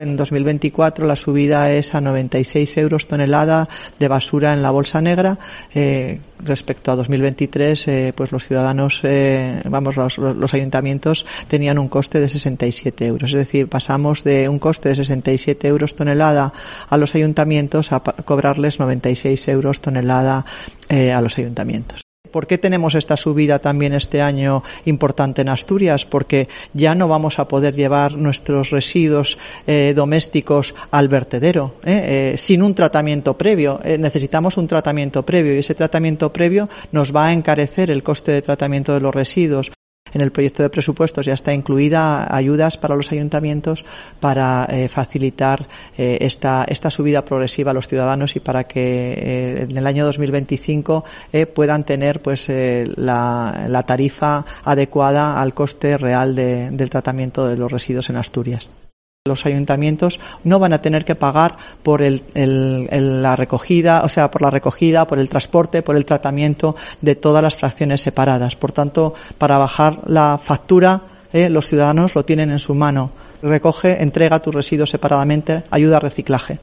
Nieves Roqueñí explica la subida de la tasa de basuras